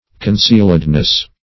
Meaning of concealedness. concealedness synonyms, pronunciation, spelling and more from Free Dictionary.